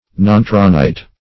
Search Result for " nontronite" : The Collaborative International Dictionary of English v.0.48: Nontronite \Non"tro*nite\, n. [So called because found in the arrondissement of Nontron, France.]
nontronite.mp3